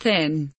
thin kelimesinin anlamı, resimli anlatımı ve sesli okunuşu